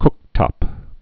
(kktŏp)